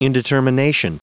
Prononciation du mot indetermination en anglais (fichier audio)
Prononciation du mot : indetermination